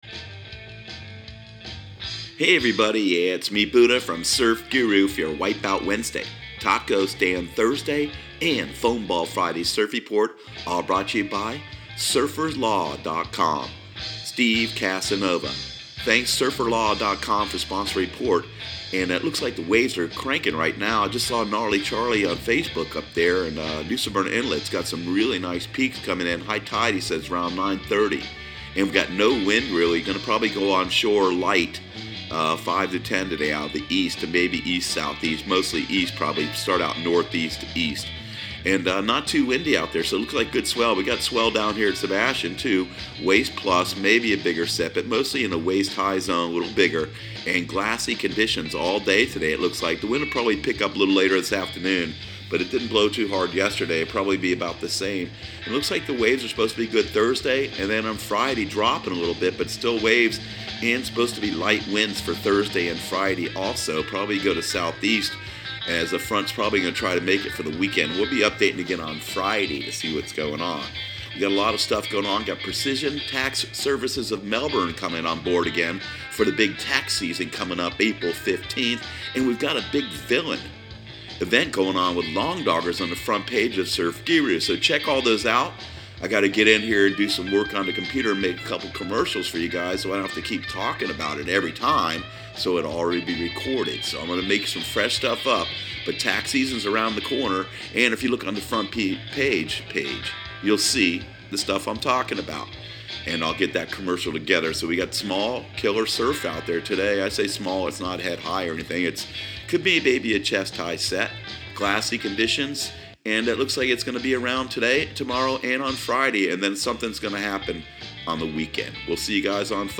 Surf Guru Surf Report and Forecast 02/06/2019 Audio surf report and surf forecast on February 06 for Central Florida and the Southeast.